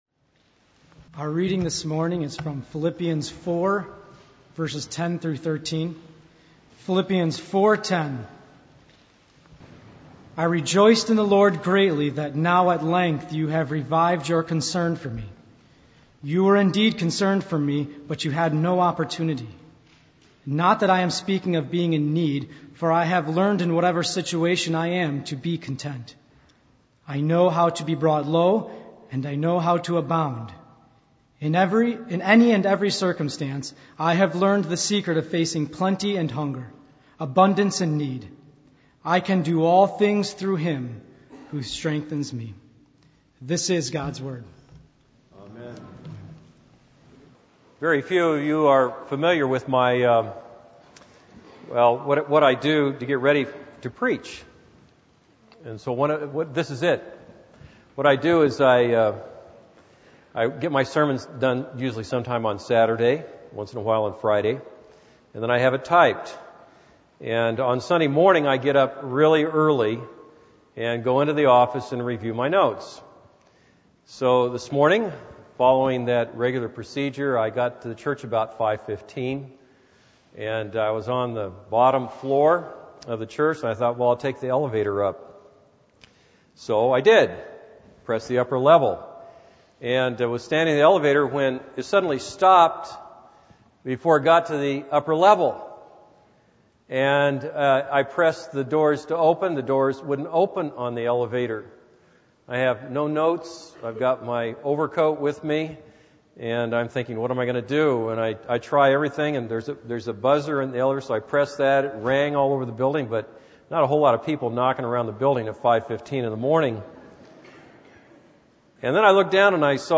This is a sermon on Philippians 4:10-13.